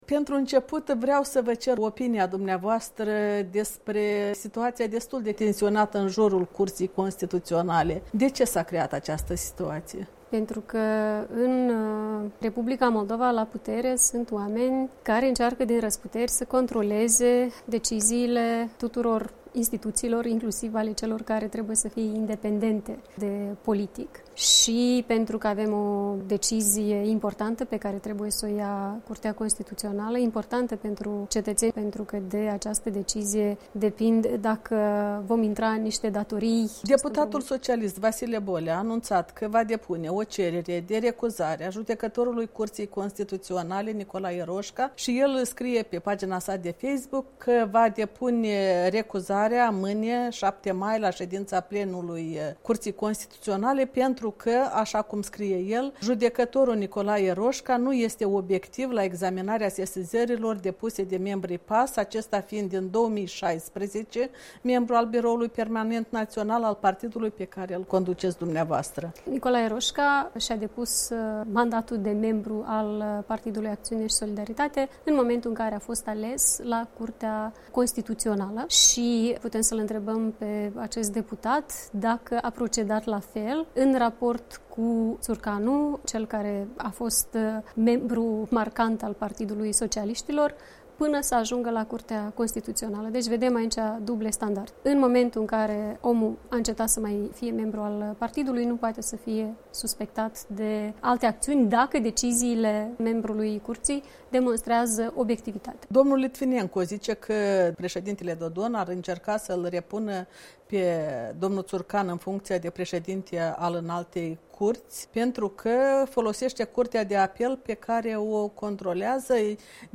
Interviu cu Maia Sandu